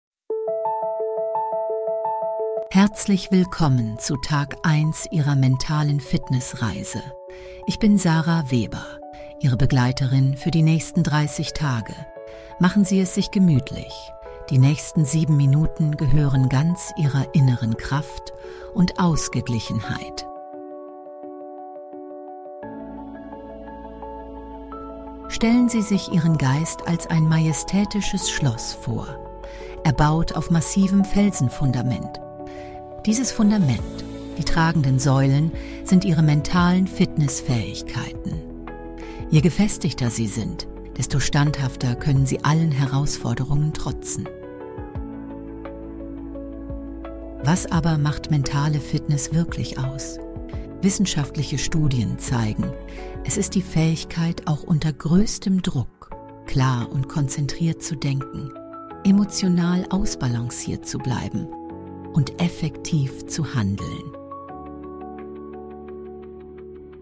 Kostenlose Body-Scan-Hörprobe